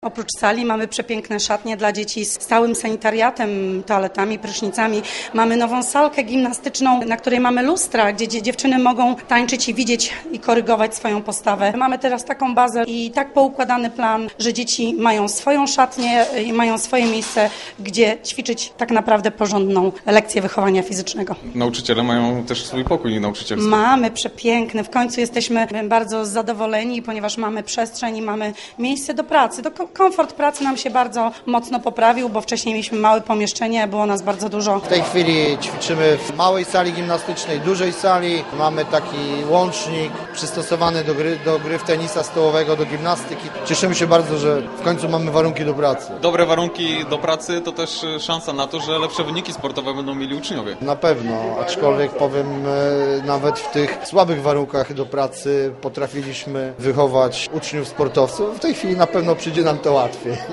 nauczyciele wychowania fizycznego.